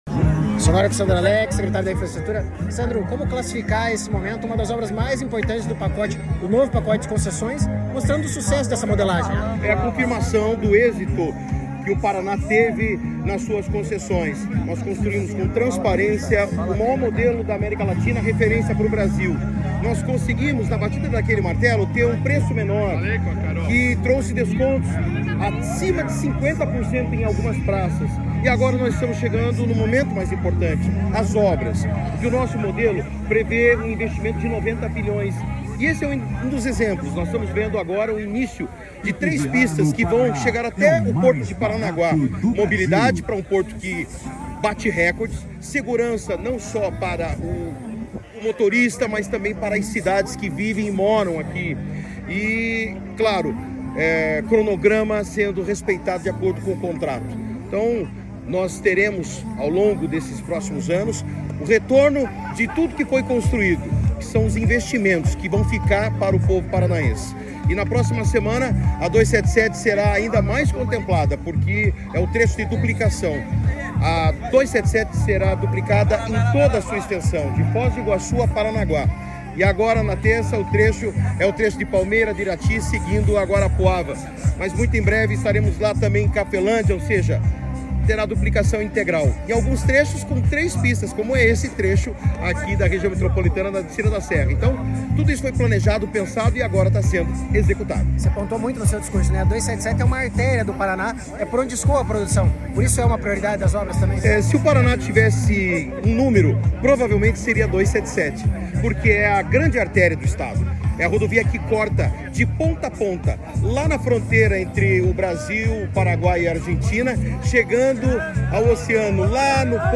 Sonora do secretário Estadual de Infraestrutura e Logística, Sandro Alex, sobre as obras de novas faixas na BR-277, entre Curitiba e São José dos Pinhais